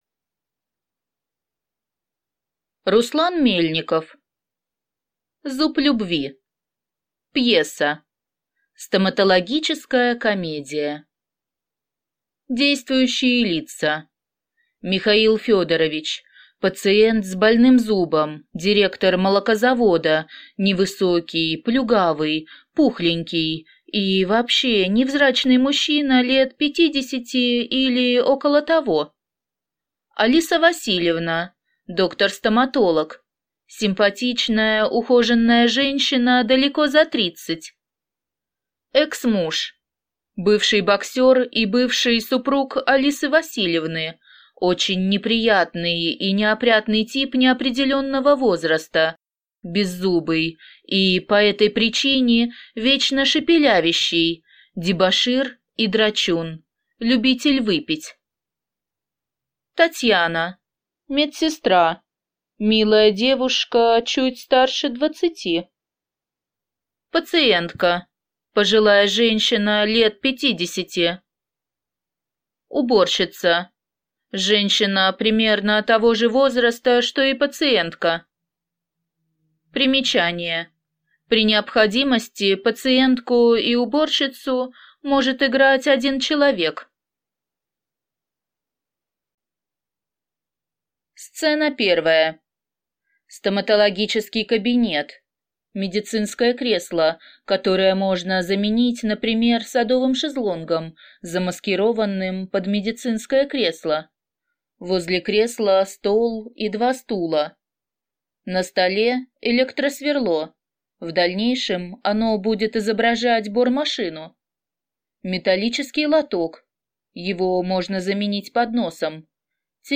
Аудиокнига Зуб любви